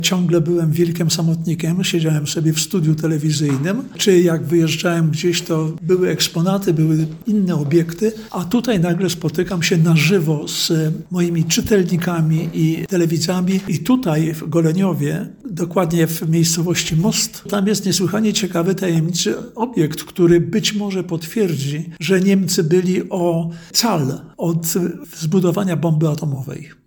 Wykład twórcy kultowych „Sensacje XX Wieku” odbył się w sali konferencyjnej magistratu.